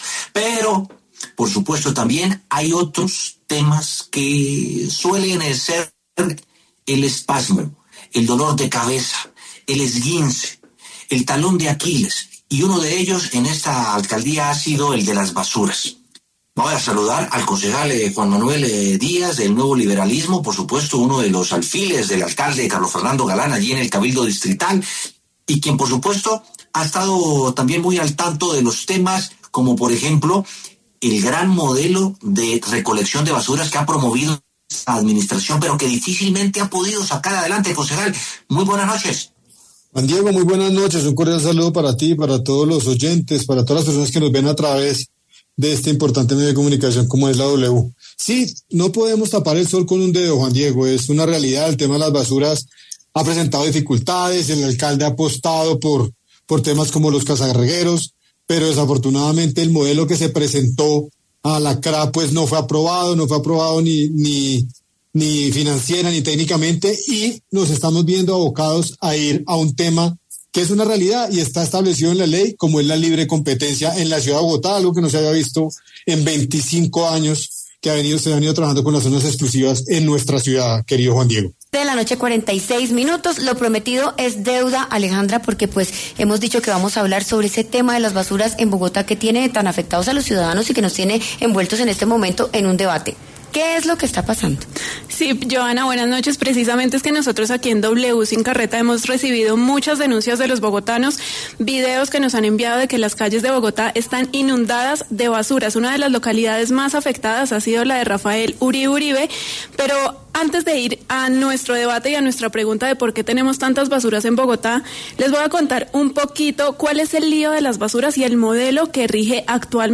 Debate: ¿Bogotá vive un caos en el sistema de recolección de basuras?
Los concejales Juan Manuel Díaz y Daniel Briceño, y el presidente del Concejo de Bogotá, Juan David Quintero, hablaron en W Sin Carreta, sobre la coyuntura que vive el país en materia de recolección de basuras.